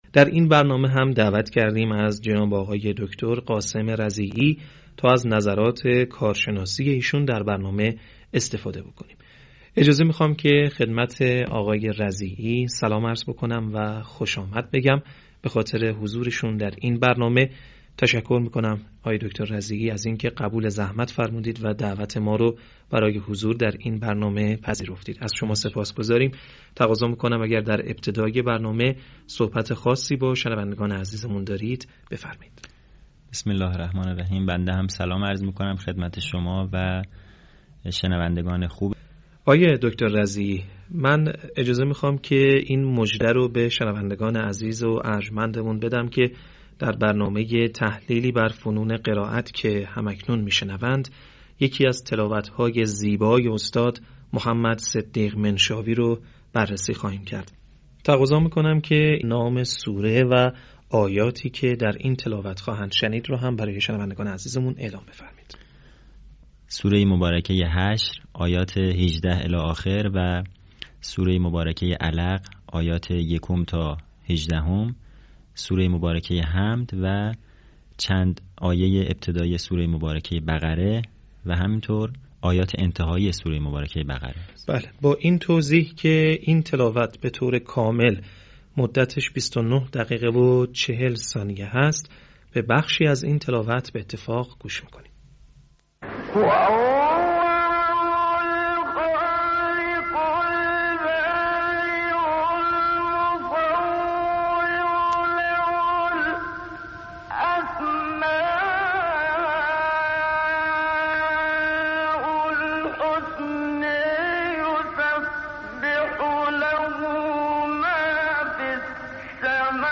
صوت | تحلیل تلاوت «منشاوی» از سور‌ حشر و علق
تحلیل تلاوت محمدصدیق منشاوی از سوره حشر و علق